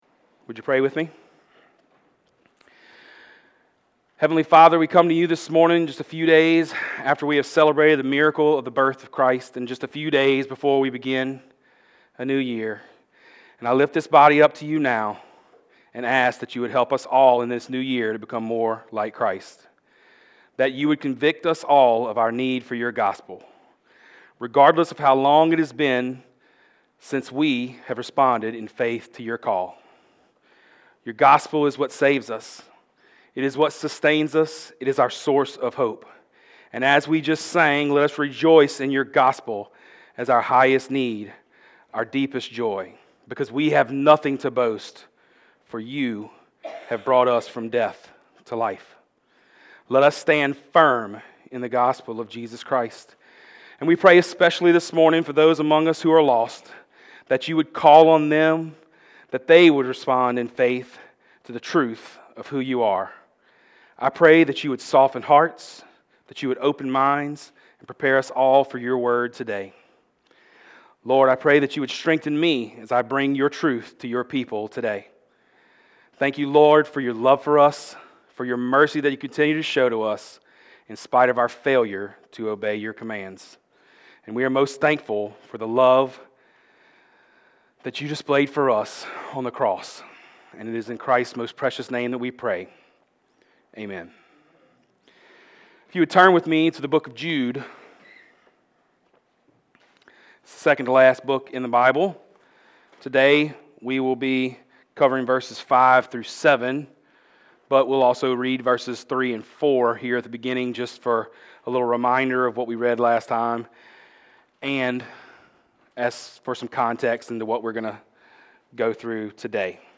Sermons | Evans Creek Baptist Church